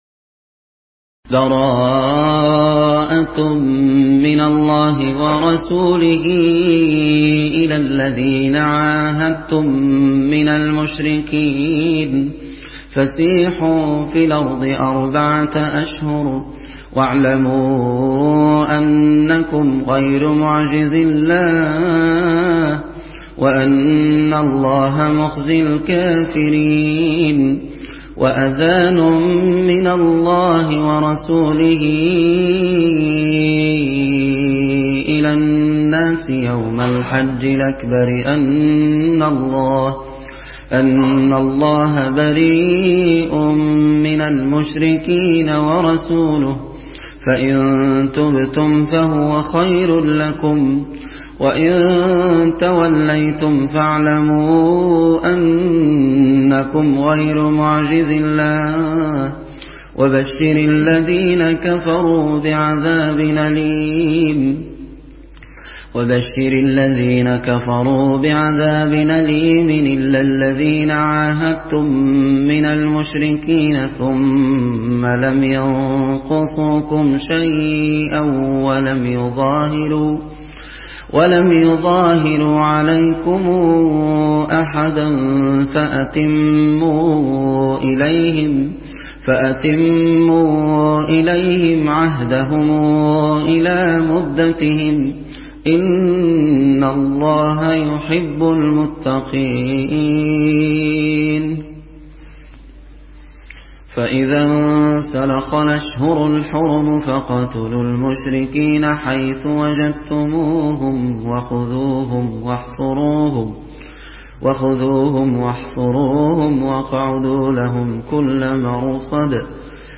Riwayat Warsh from Nafi